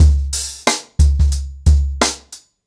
TrackBack-90BPM.78.wav